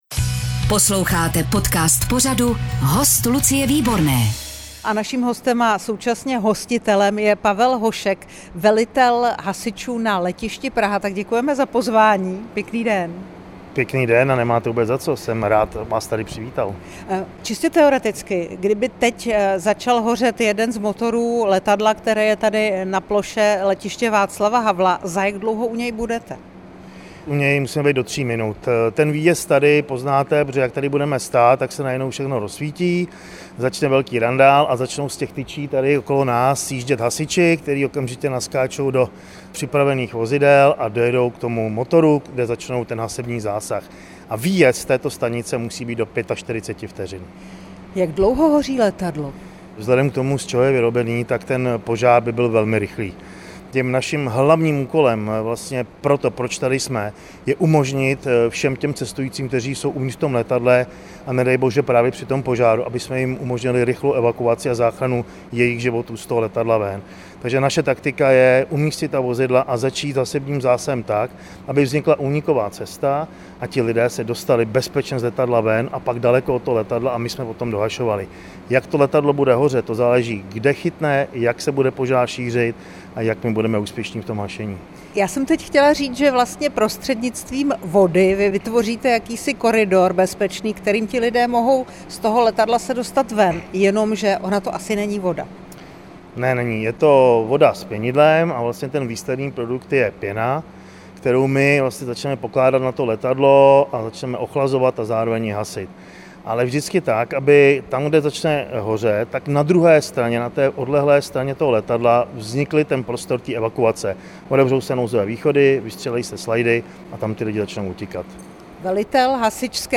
Páteční finiš Kateřiny Neumannové: Svého hosta zpovídá bývalá běžkyně na lyžích, olympijská vítězka ze ZOH v Turíně 2006, šestinásobná olympijská medailistka, dvojnásobná mistryně světa - 20.06.2025